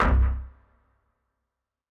INT Stab C1.wav